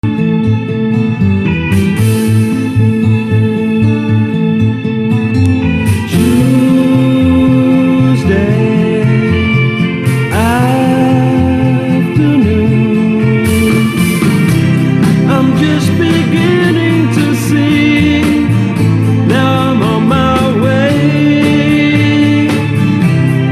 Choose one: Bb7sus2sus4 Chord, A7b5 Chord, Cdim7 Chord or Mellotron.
Mellotron